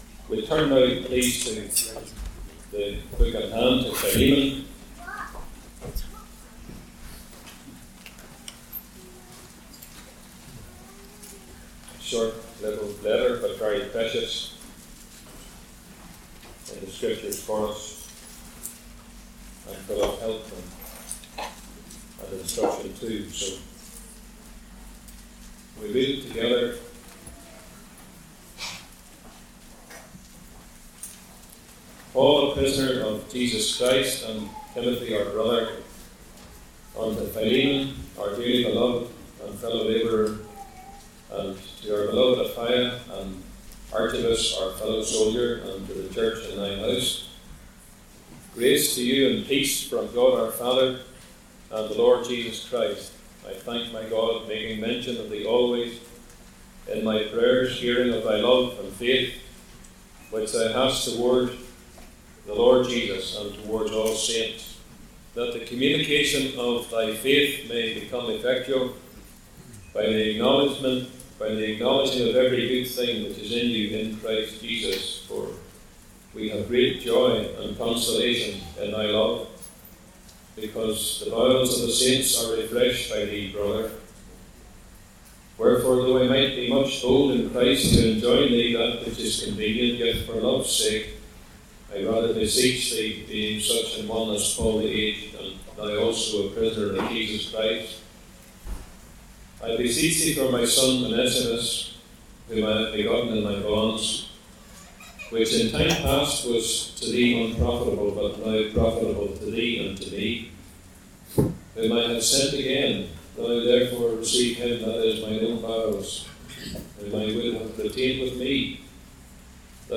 Philemon – Bible Reading
Location: Cooroy Gospel Hall (Cooroy, QLD, Australia)